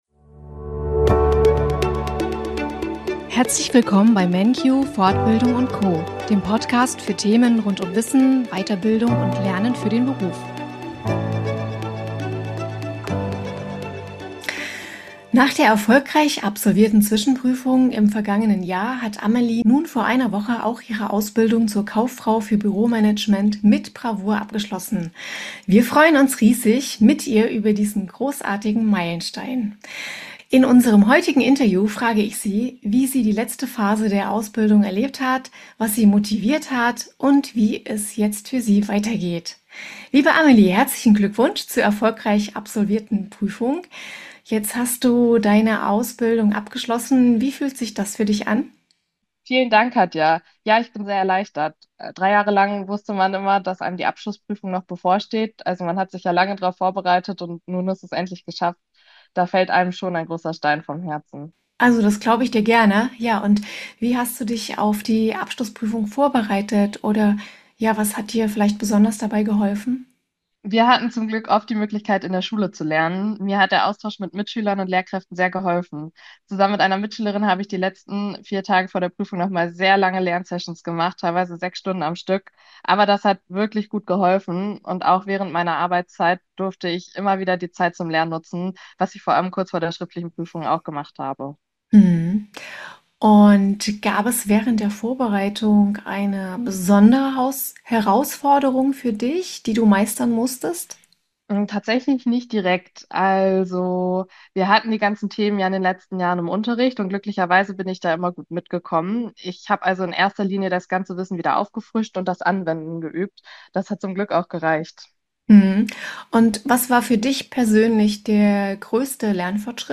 manQ - viel Spaß beim Interview.